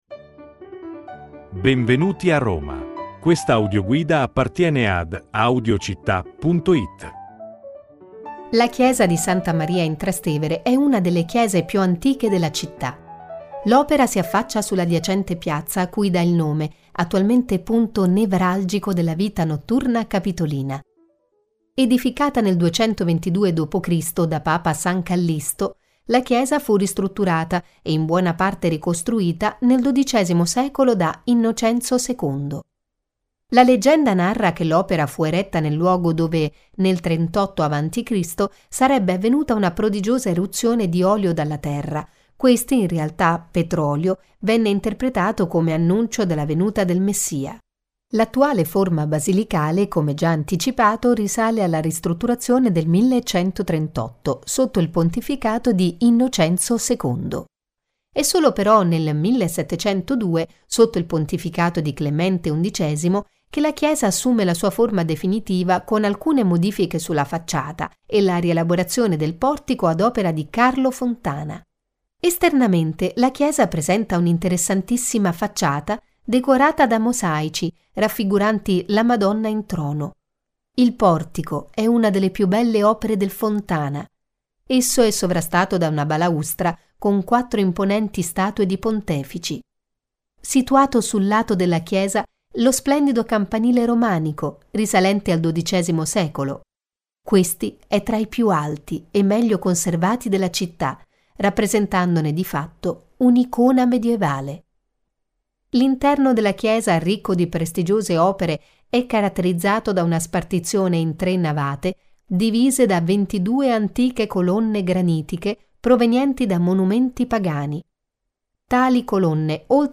Audioguida Roma - Santa Maria in Trastevere - Audiocittà